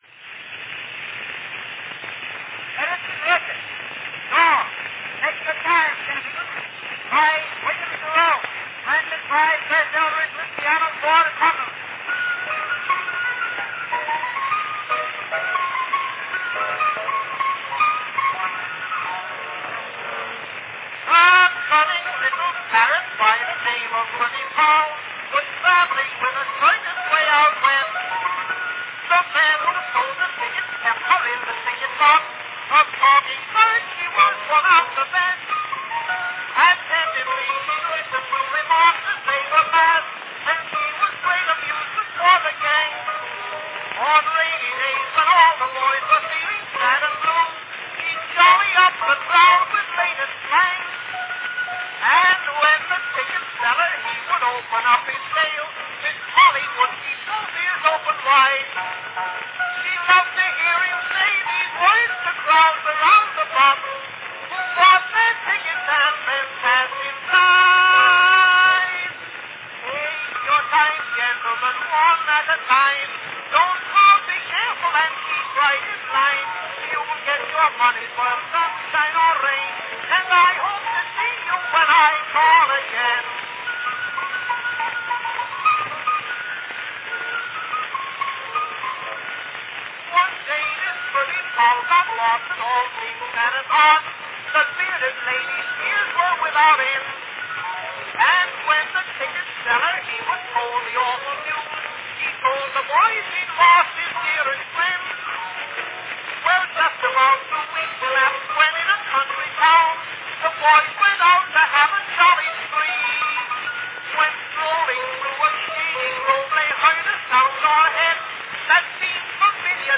an early brown wax cylinder recording
Category Song